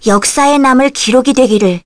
Leo-Vox_Skill5_kr.wav